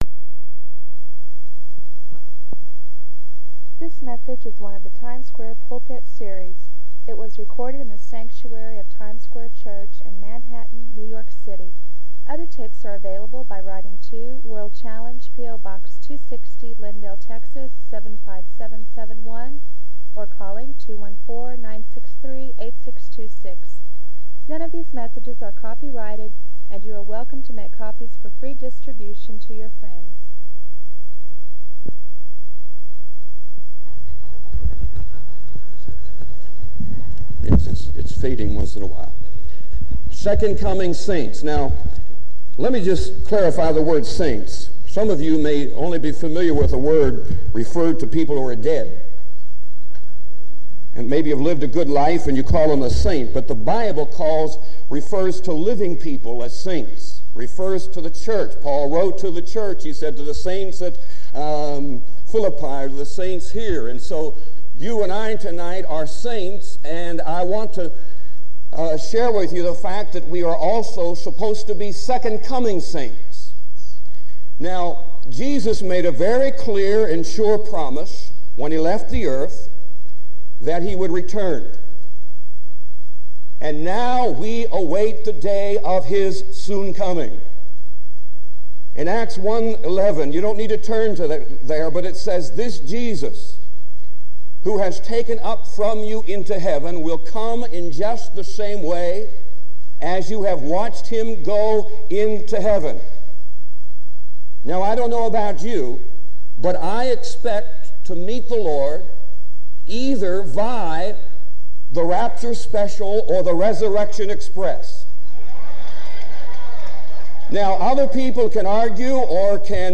This sermon challenges Christians to examine their faith and live in holiness as they await the blessed hope.